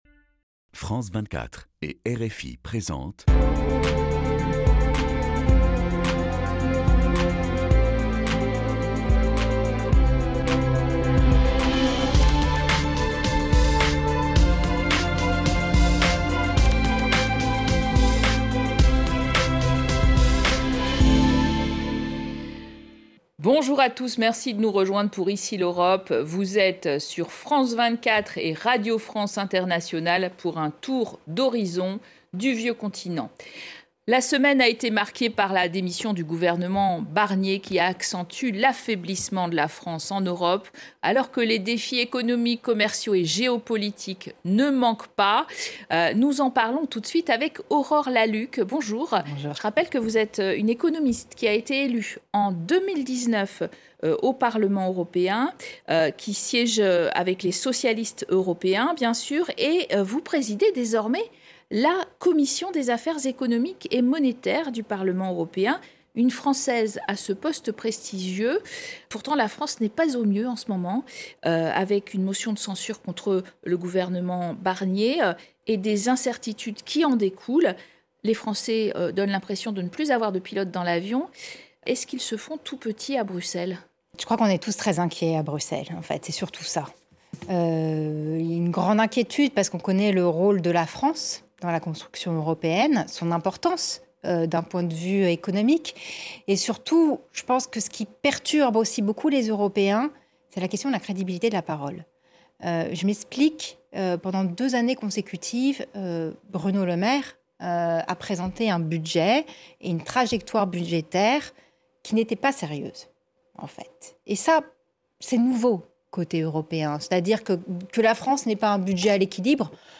Cette semaine, nous recevons Aurore Lalucq, députée européenne de l’Alliance des socialistes et démocrates et présidente de la Commission des Affaires économiques et monétaires au Parlement européen. Elle revient sur la motion de censure qui a fait tomber le gouvernement en France et sur la manière dont Bruxelles perçoit cette crise politique.